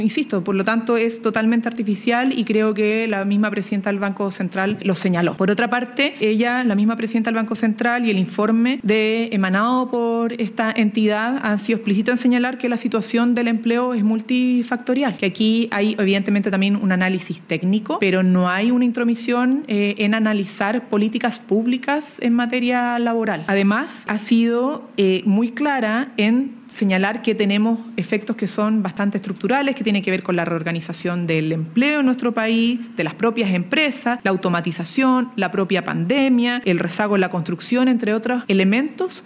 Durante su tradicional punto de prensa de los lunes, la secretaria de Estado manifestó que el informe del Banco Central reconoce que los efectos sobre el empleo son multifactoriales y señaló que en ningún momento se ha intentado que la entidad se inmiscuya en decisiones de política pública laboral.